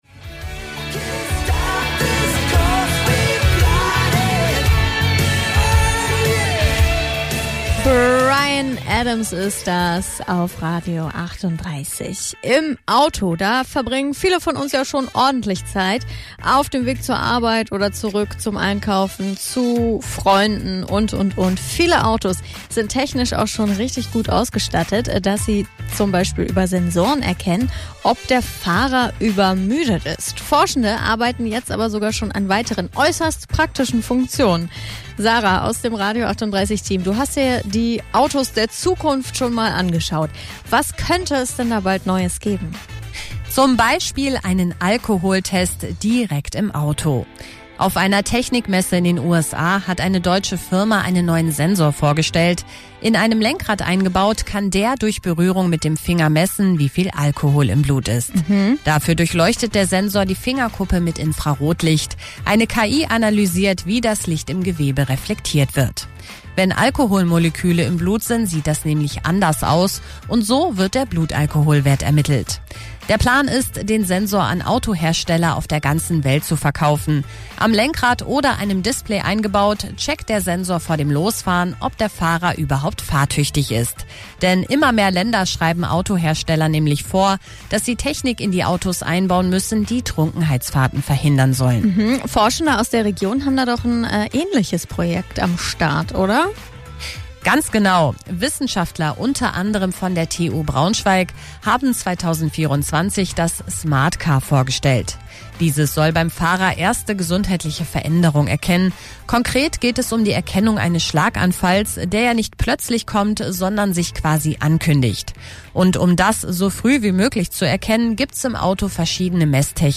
Im aktuellen Beitrag stand erneut die Frage im Mittelpunkt, wie Fahrzeuge mithilfe moderner Sensorik und intelligenter Datenanalyse Hinweise auf den Gesundheitszustand von Fahrerinnen und Fahrern liefern können. In einem kurzen Interview